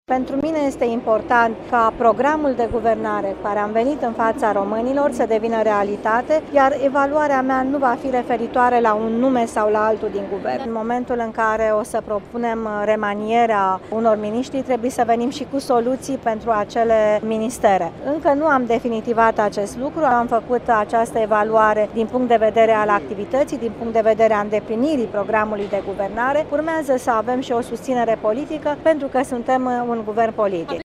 Premierul Viorica Dăncilă a făcut noi precizări referitoare la remanierea Cabinetului său. Ea a spus aseară că evaluarea miniştrilor a vizat îndeplinirea programului de guvernare şi remanierea ar întîrzia pentru că nu au fost încă stabilite persoanele care îi vor înlocui pe cei care îşi vor pierde portofoliiile :